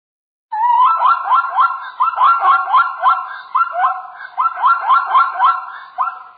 zebra7.wav